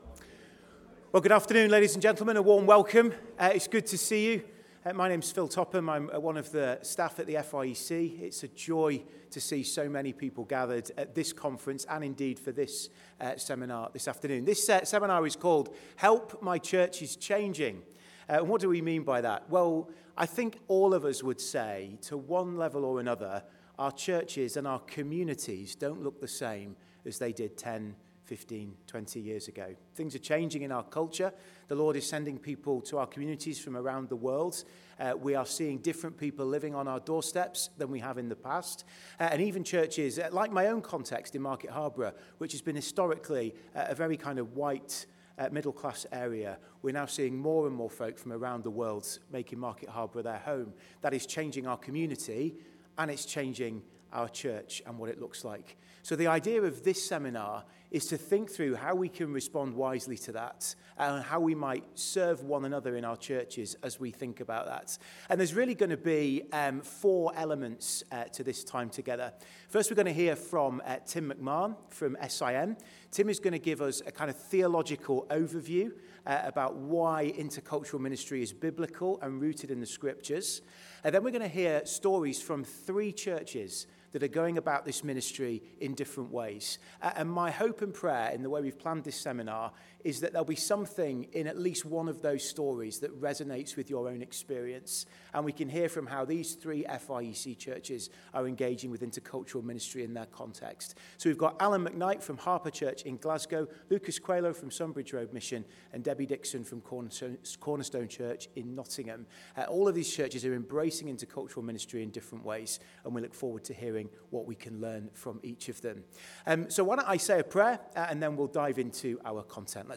As our communities become more diverse, how do we take the first steps towards becoming an intercultural church? The FIEC Intercultural Ministry team lead a seminar at the 2025 Leaders' Conference.